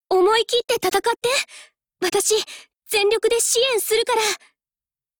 Cv-10729_warcry.mp3